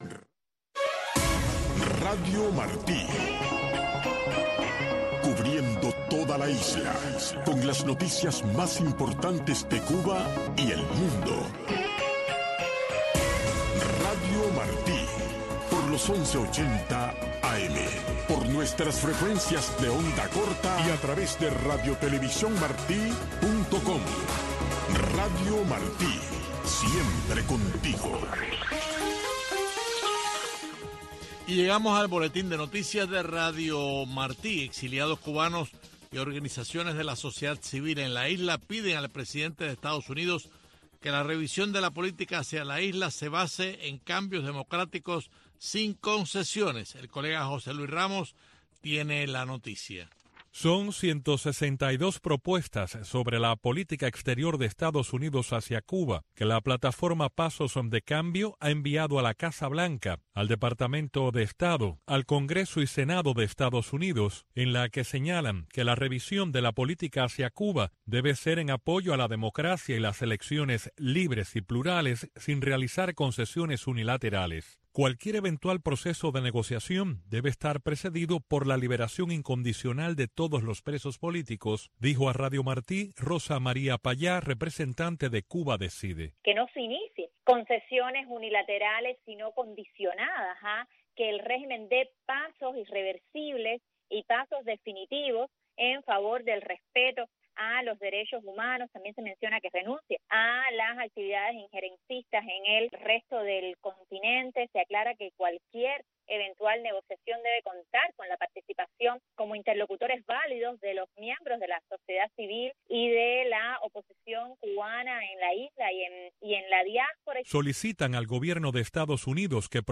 Un desfile de éxitos de la música pop internacional, un conteo regresivo con las diez canciones más importantes de la semana, un programa de una hora de duración, diseñado y producido a la medida de los jóvenes cubanos.